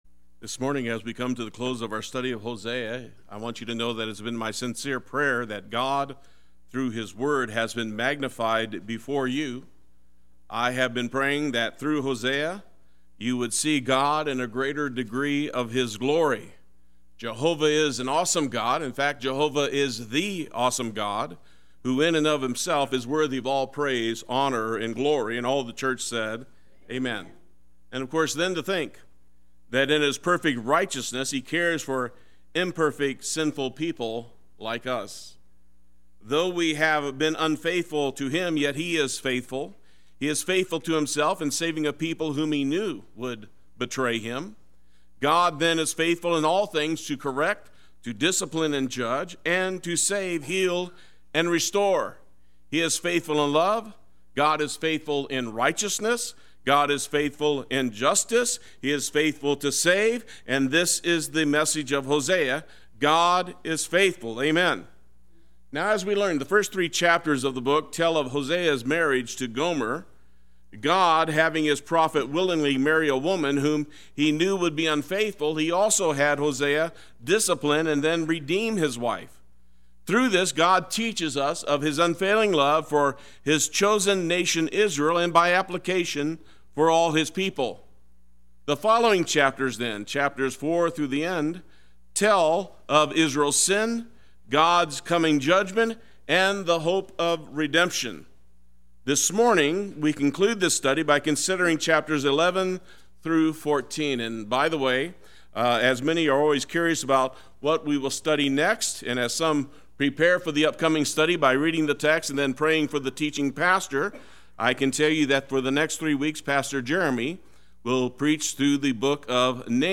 Play Sermon Get HCF Teaching Automatically.
The Ways of the Lord are Right Sunday Worship